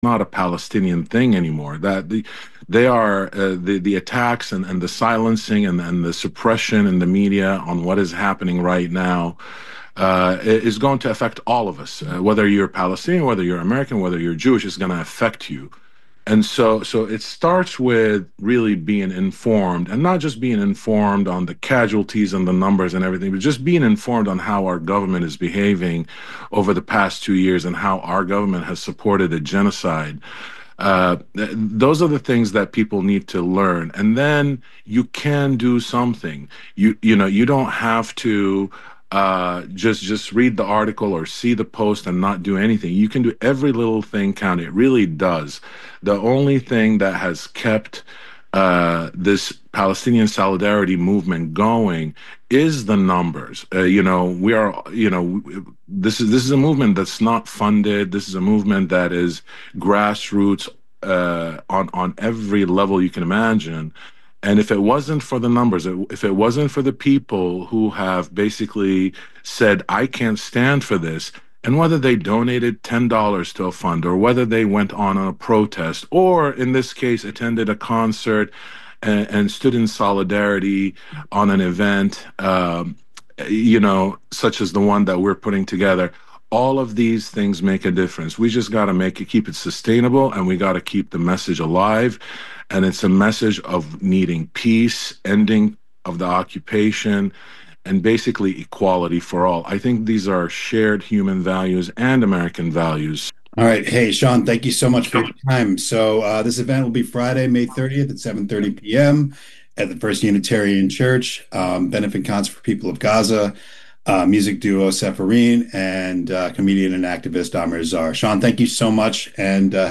This Way Out is the only internationally distributed weekly LGBTQ radio program, currently airing on some 200 local community radio stations around the world. The award-winning half-hour magazine-style program features a summary of some of the major news events in or affecting the queer community (NewsWrap), in-depth coverage of major events, interviews with key queer figures, plus music, literature, entertainment — all the information and culture of a community on the move!